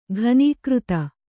♪ ghanīkřta